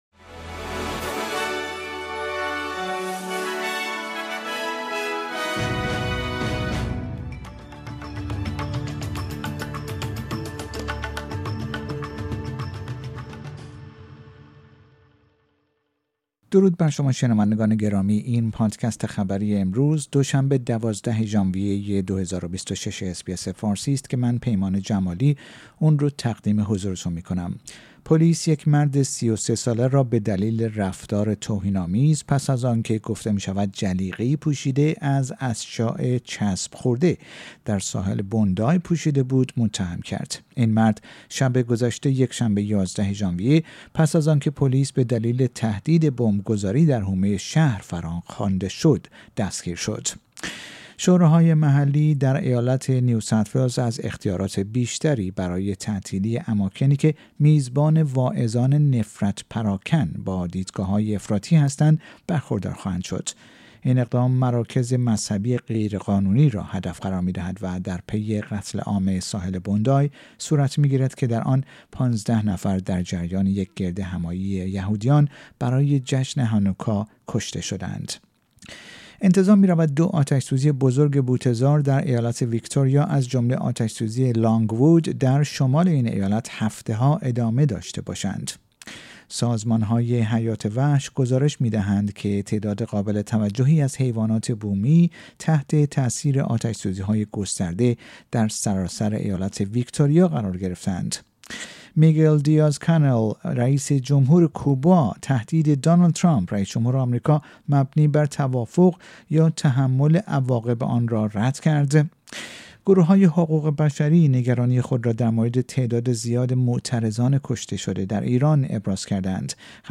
در این پادکست خبری مهمترین اخبار روز دوشنبه ۱۲ ژانویه ۲۰۲۶ ارائه شده است.